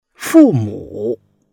fu4mu3.mp3